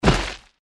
target_impact_only.ogg